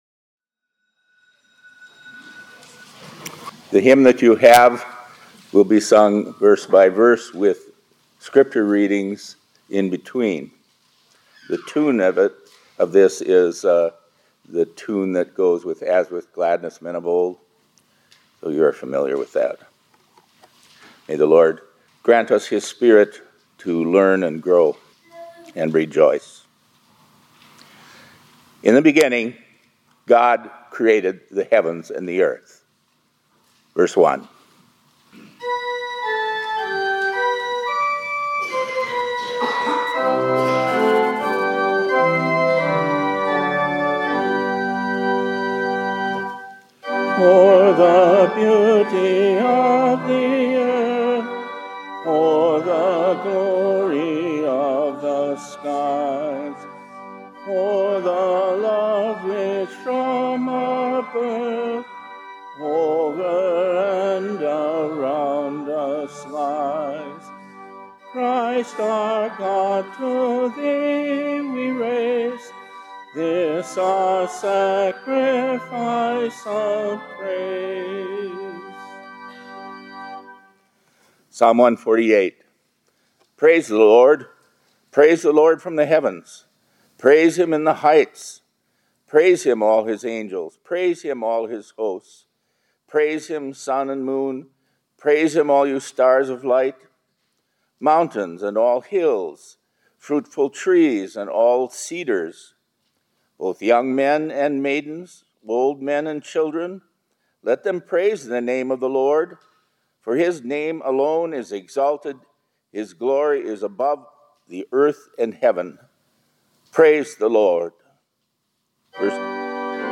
2024-11-27 ILC Chapel — Thanksgiving Selections